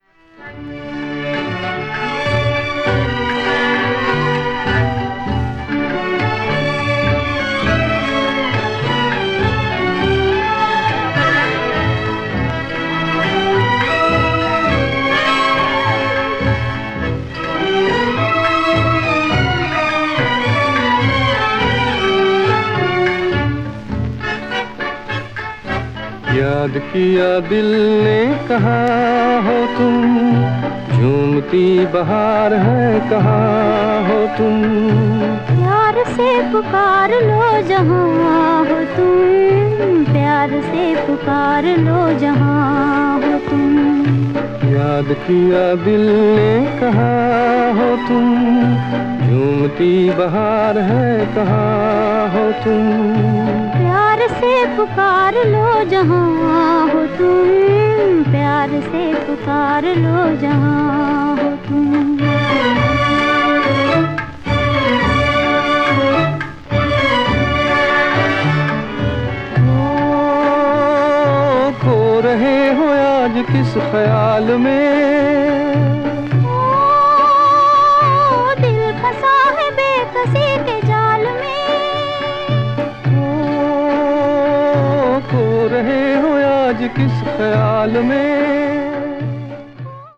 バックグラウンドノイズが入る曲がありますが、オリジナル音源に起因するものです。
bollywood   india   indian vocal   oriental   world music